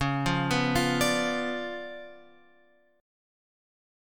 C#7b9 chord {9 8 9 10 x 10} chord